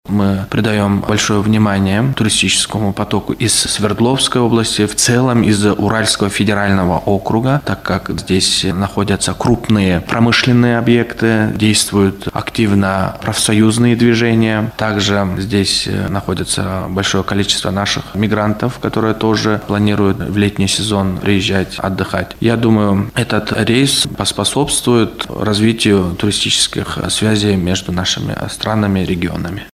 Летать в Тамчы будет авиакомпания «Уральские авиалинии» два раза в неделю. Об этом сообщил директор Департамента туризма при Министерстве экономики и коммерции Кыргызской Республики Эрденет Касымов на пресс-конференции «ТАСС-Урал».